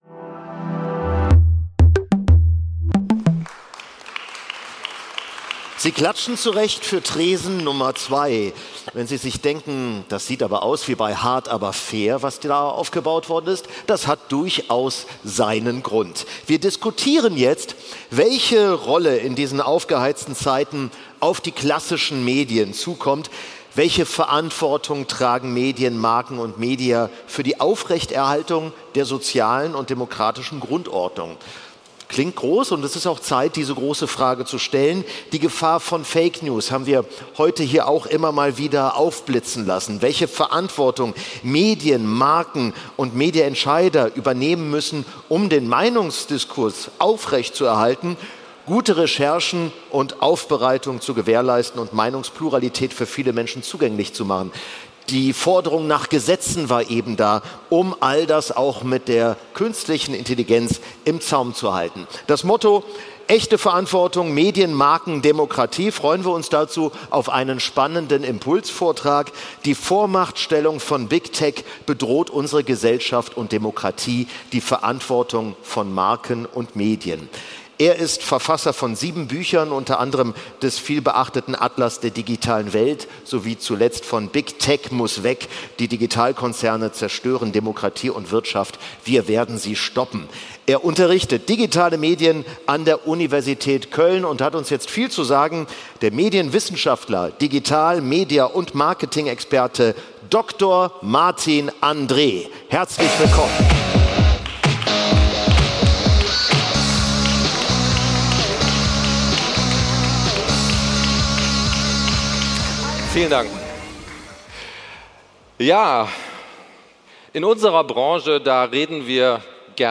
Sonderfolge: Diskussionsrunde zum Thema echte Verantwortung: Medien. Marken. Demokratie ~ Umgehört